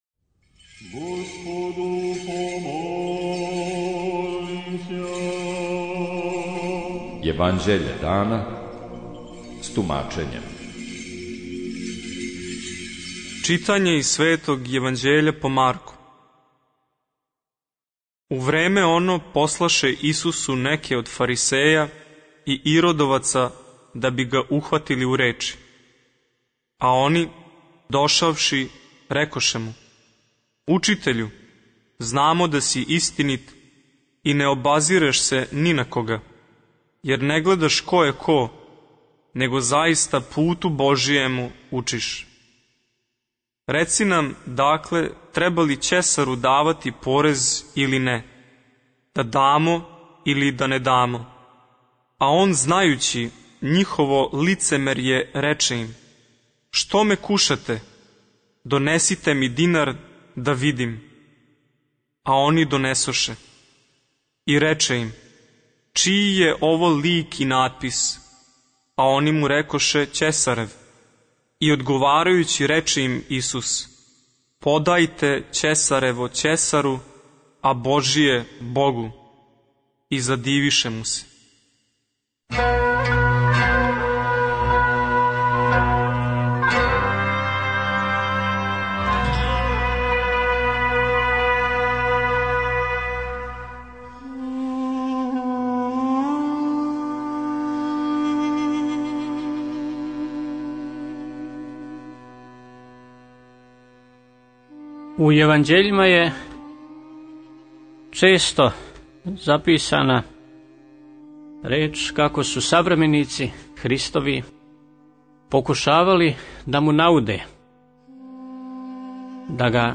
Читање Светог Јеванђеља по Матеју за дан 29.06.2023. Зачало 43.